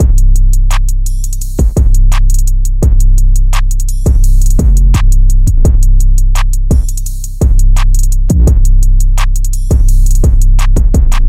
描述：气喘吁吁
标签： 170 bpm Trap Loops Drum Loops 972.97 KB wav Key : C FL Studio
声道立体声